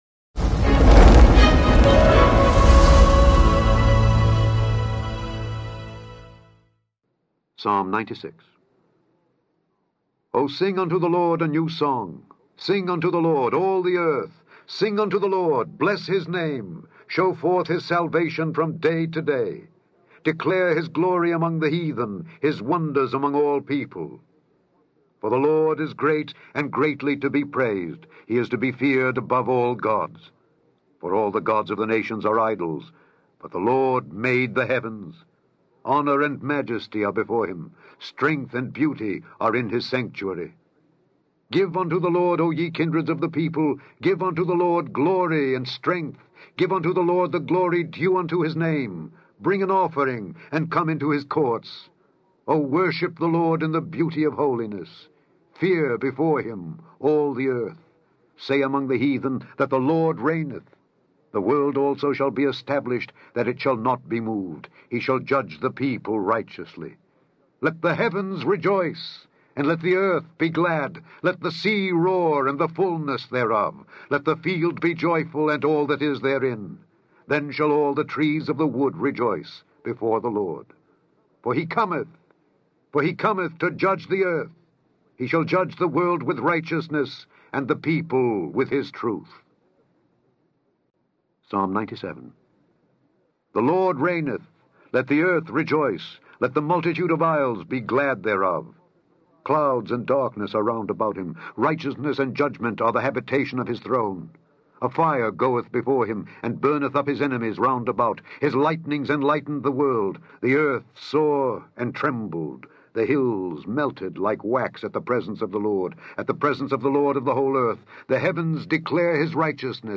Daily Bible Reading: Psalms 96-98
In this podcast, you can listen to Alexander Scourby read Psalms 96-98.